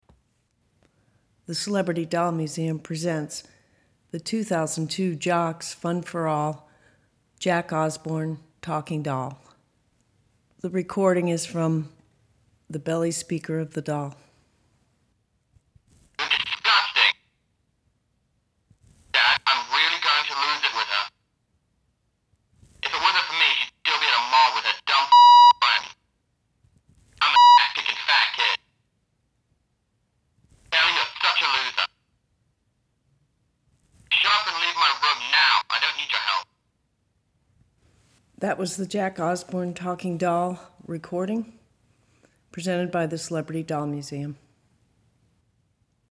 The Jack Osbourne talking doll says 6 separate expressions with a push of the stomach activation button.
CDMJackOsbournetalkingdoll.mp3